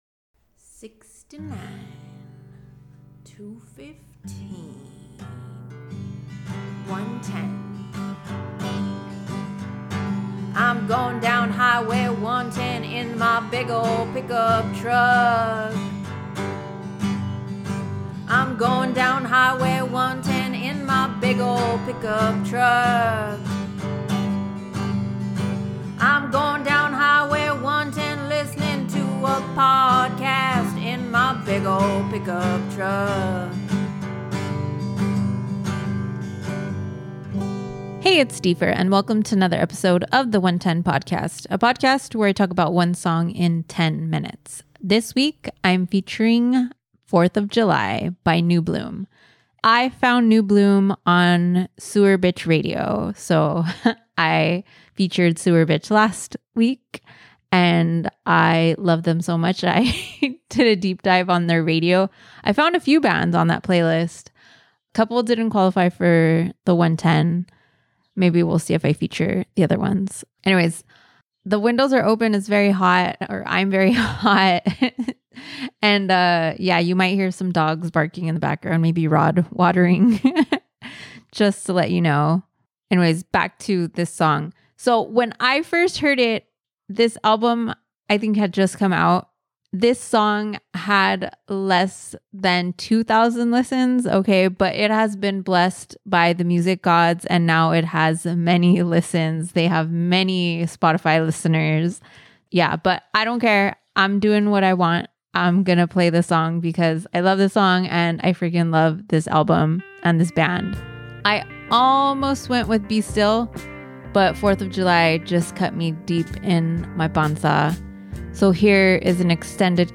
Discovered through sewerbitch! radio on Spotify, this track captivated me with its haunting guitar tone and a chord progression that reminded me of Pearl Jam’s “Immortality”—let me know if you hear it too. But it’s the lyrics that sealed the deal, a beautiful meditation on unrequited love that will resonate with anyone who’s ever had their heart broken.